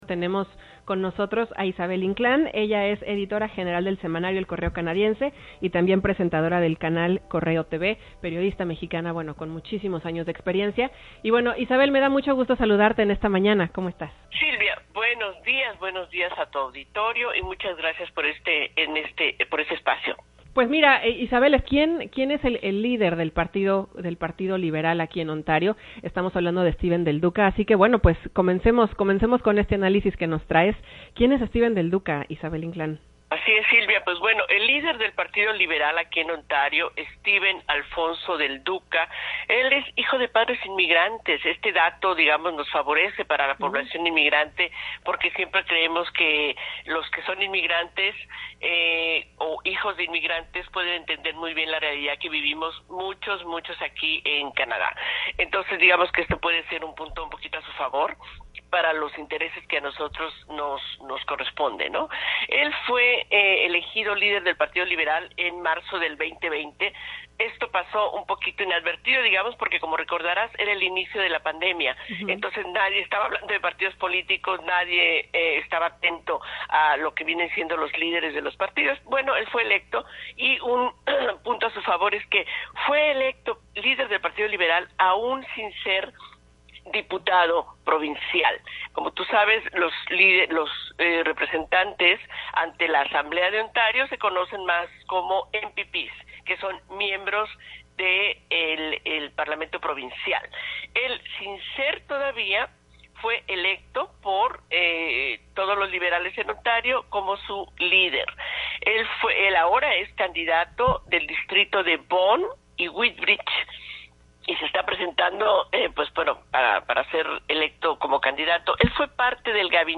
en entrevista para CHHA 1610 AM